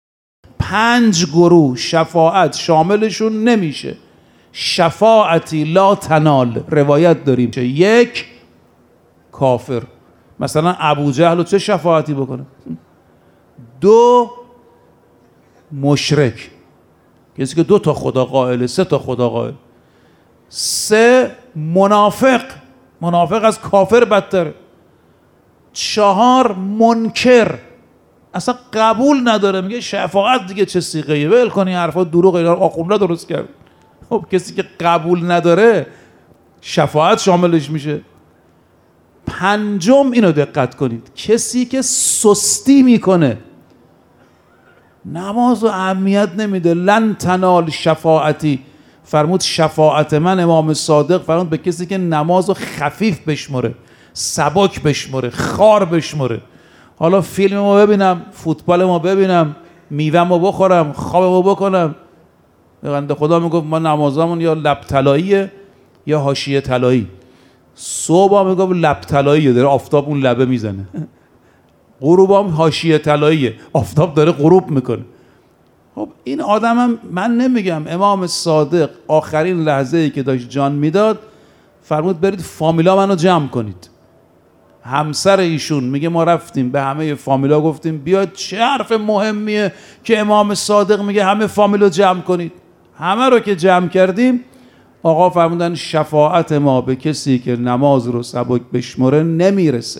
در یکی از سخنرانی های خود به موضوع «عدم شفاعت پنچ گروه خاص در روز قیامت» پرداخت